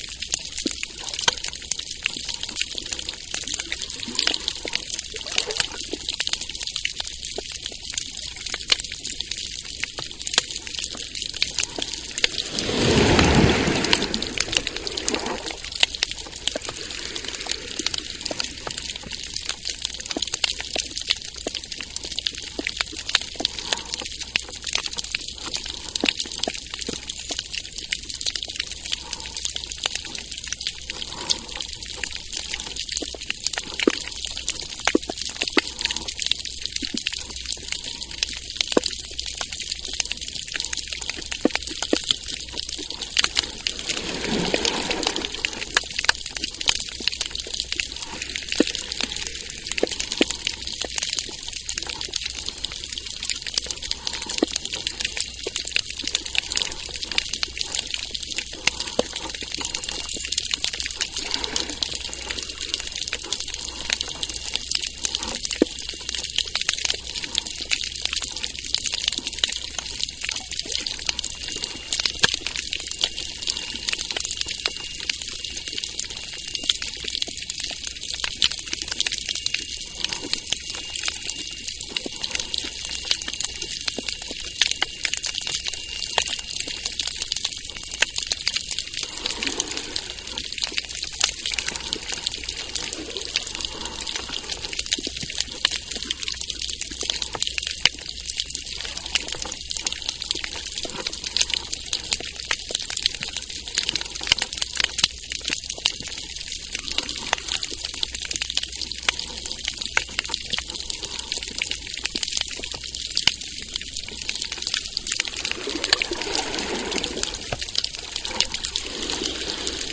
Demonstration soundscapes
anthropophony
geophony
biophony